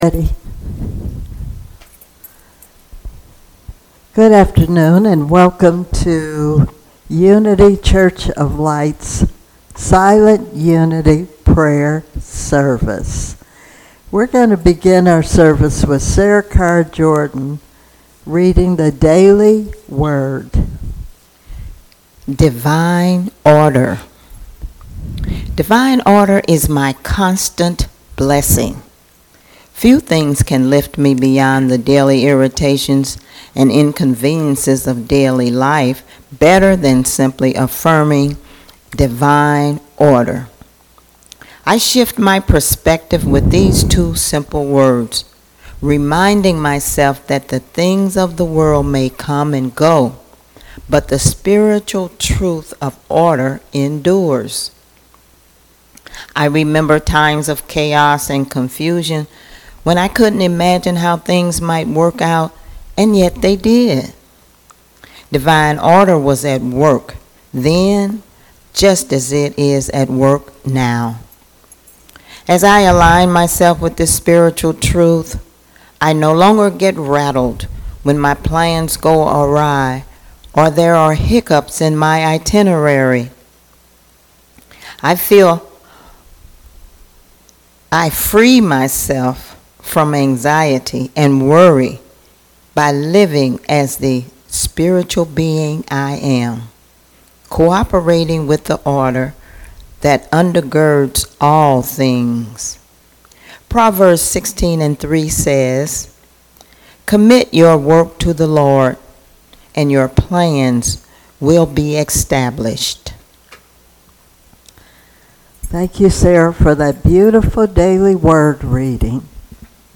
07/09/2023 - Silent Unity Prayer Service